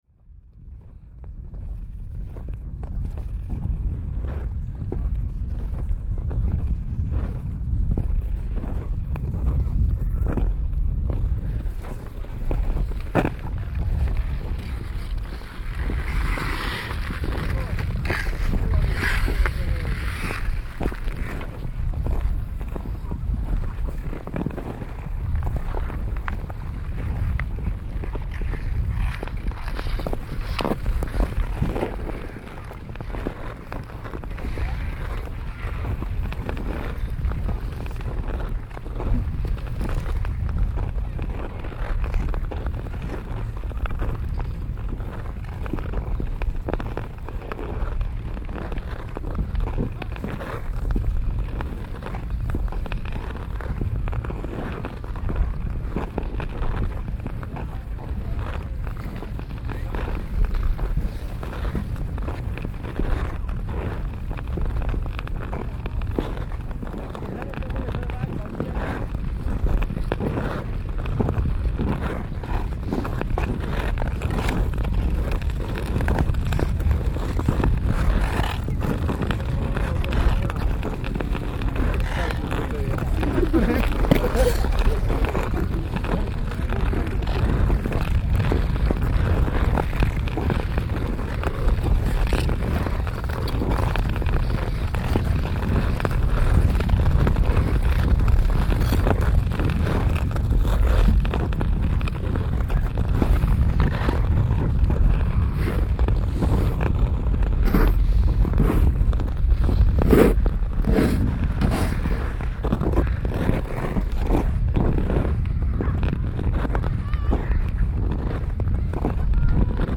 field recordings, sound art, radio, sound walks
Bruslení po Botiči v Hostivaři
Tagy: exteriér periferie příroda voda sníh sport
Nahrávka na binaurální mikrofon, posouchat se sluchátky, (pokud možno na uších).